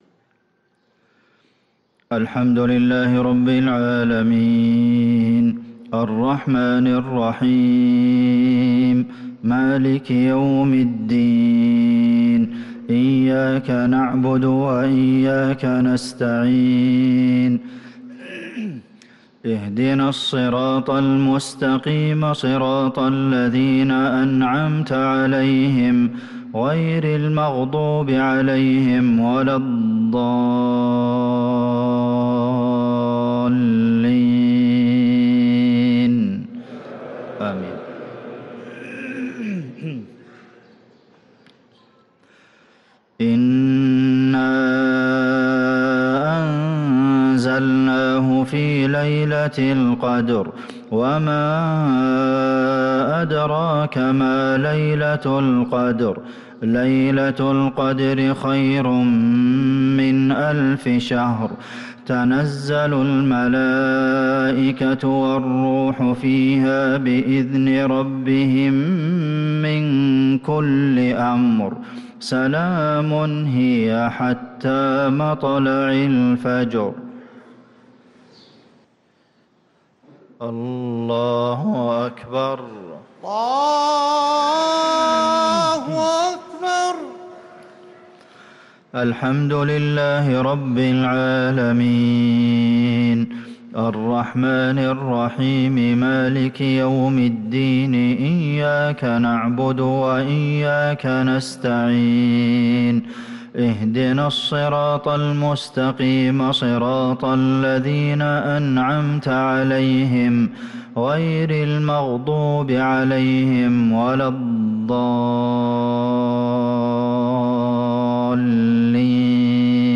صلاة المغرب للقارئ عبدالمحسن القاسم 5 رجب 1445 هـ
تِلَاوَات الْحَرَمَيْن .